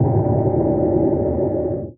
sonarTailSuitVeryCloseShuttle3.ogg